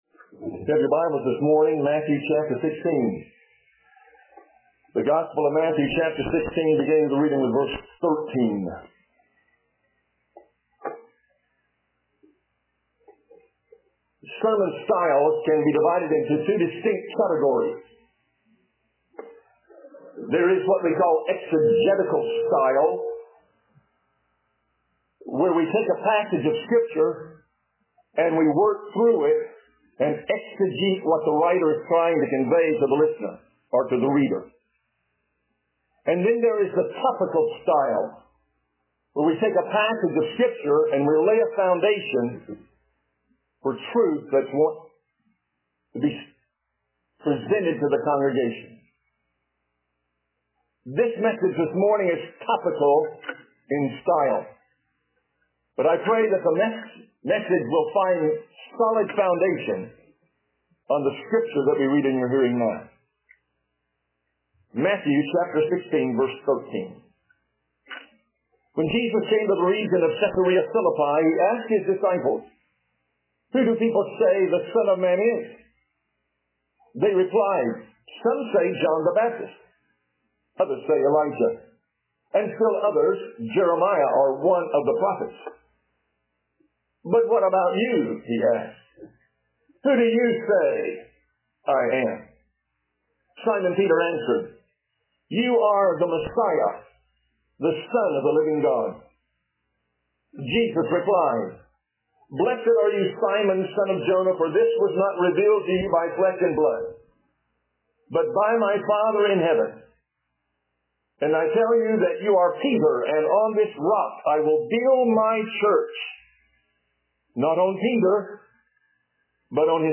Wed-AM-Service-2018-Conference.mp3